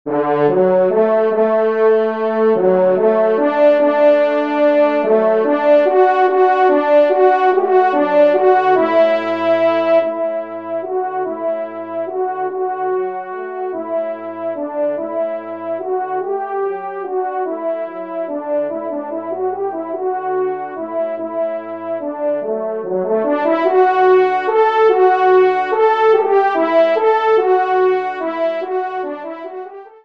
Genre :  Divertissement pour Trompes ou Cors en Ré
1e Trompe             2e Trompe